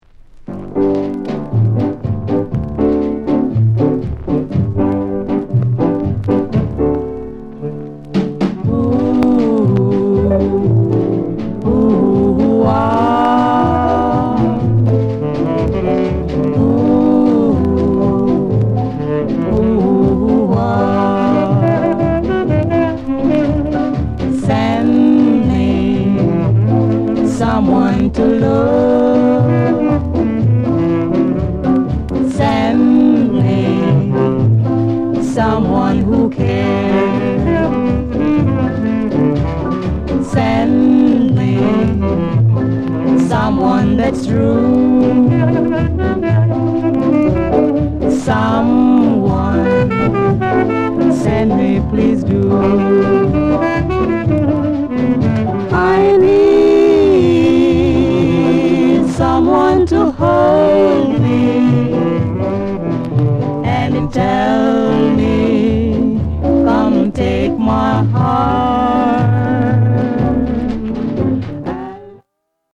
SOUND CONDITION A SIDE VG
RARE SKA INST